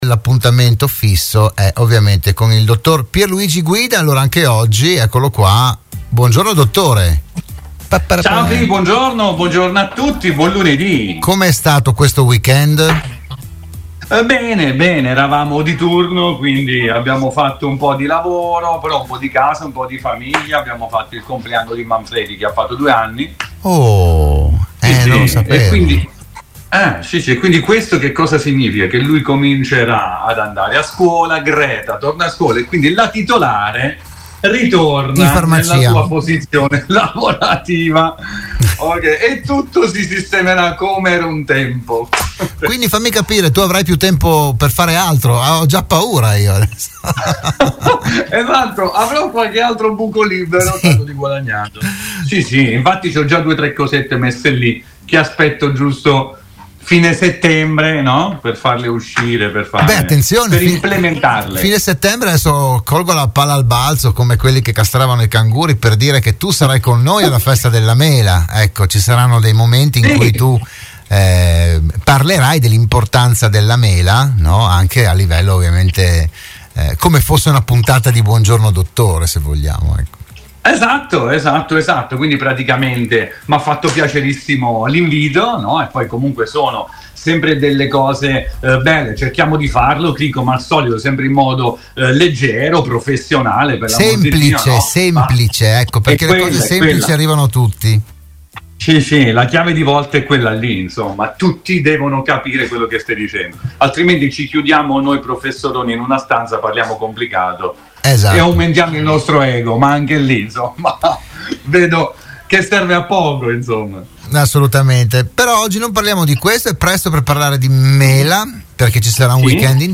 parla in modo semplice e chiaro di salute e benessere